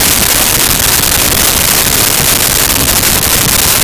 Train Loop
Train Loop.wav